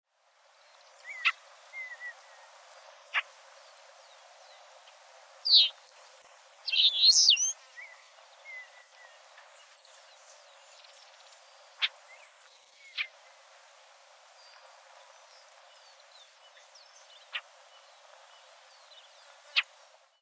Saffron-cowled Blackbird (Xanthopsar flavus)
Sex: Both
Life Stage: Adult
Province / Department: Entre Ríos
Condition: Wild
Certainty: Observed, Recorded vocal